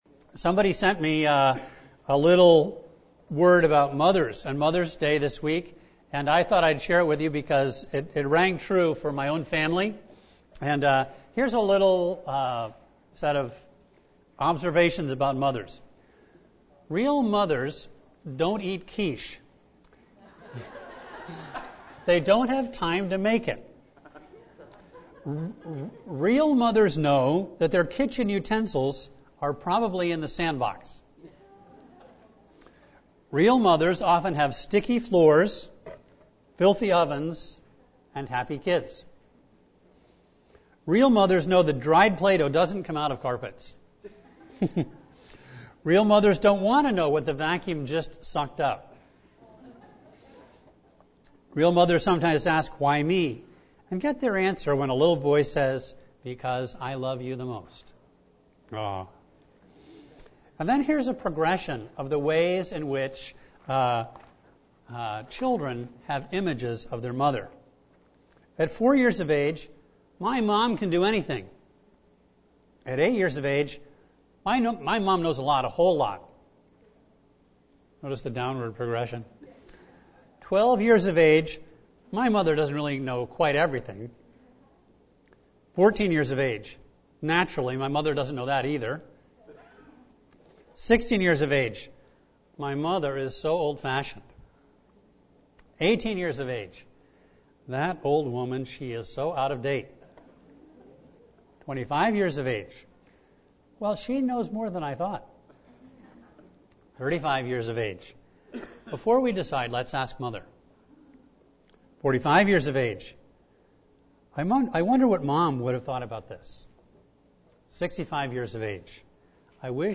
A message from the series "40 Days of Love."